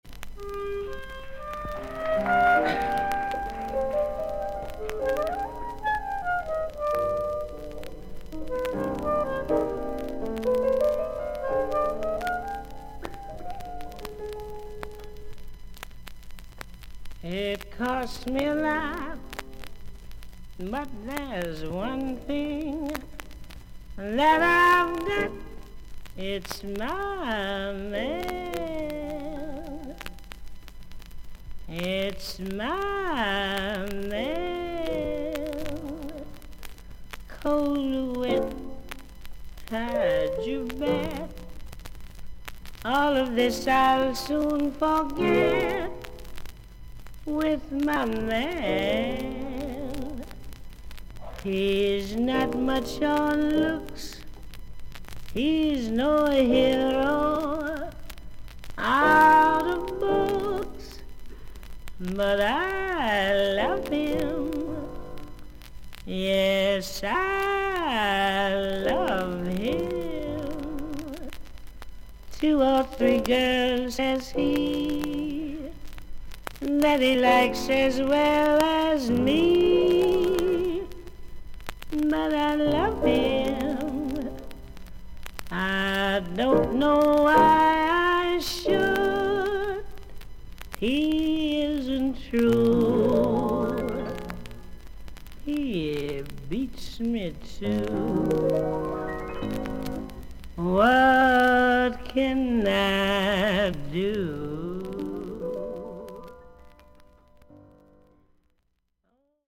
全体的に大きめのサーフィス・ノイズあり。少々軽いパチノイズの箇所あり。
女性ジャズ・シンガー。1956年11月に行われたカーネギー・ホールでのコンサートを収録。